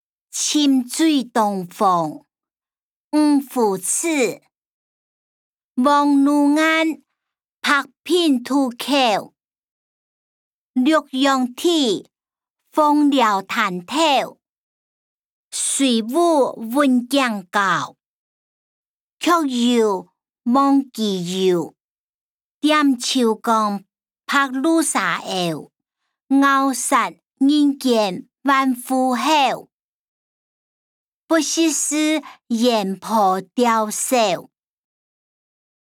詞、曲-沉醉東風：漁父詞音檔(饒平腔)